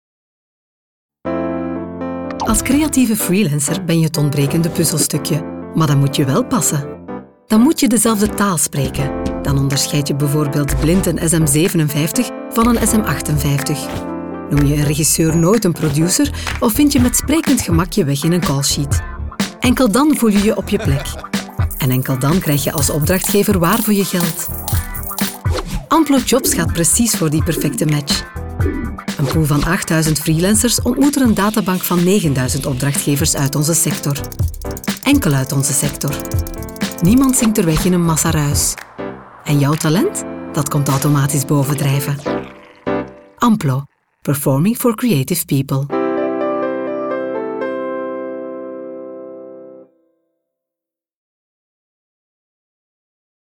Diep, Natuurlijk, Vertrouwd, Vriendelijk, Warm
Commercieel